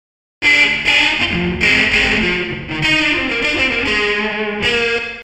（キー F /テンポ 100 に変更）
3. AmpSim → Chorus → Reverb → Comp
リバーブは、プリセットのままなので非現実的なくらい濃い目ですね。